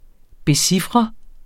[ beˈsifʁə ]